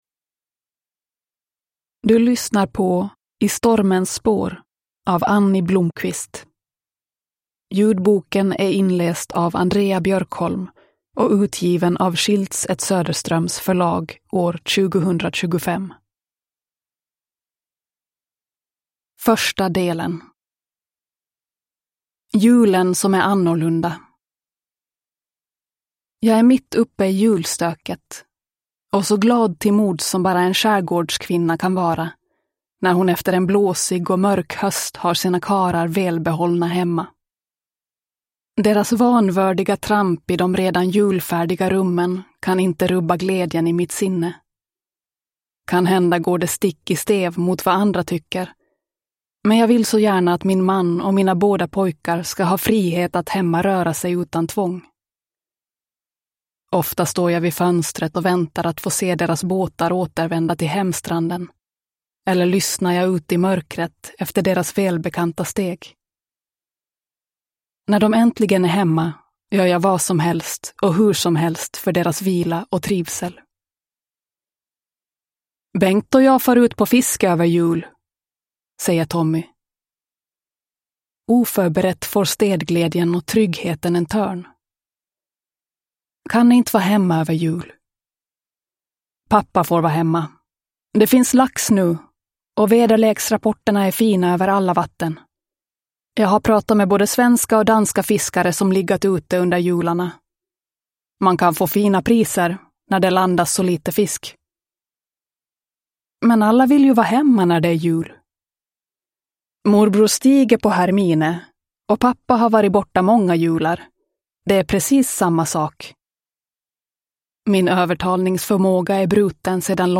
I stormens spår – Ljudbok